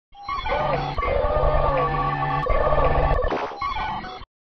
In terms of sound design, I have combined most of the sound effects on Reaper to create a feeling of “bargaining”.
Mechanical_02.wav